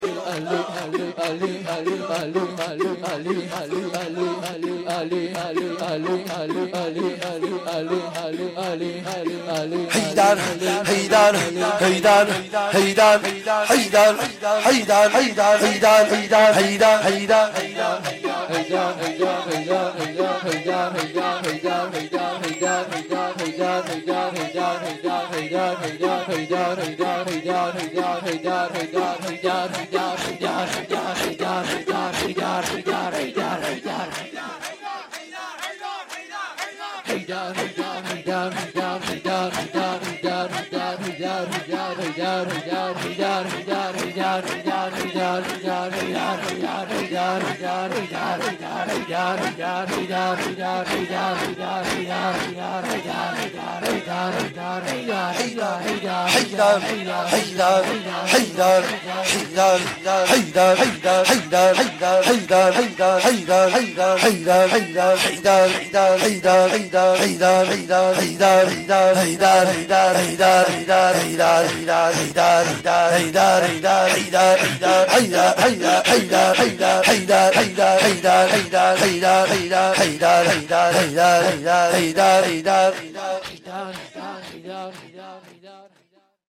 روضه
zekr.mp3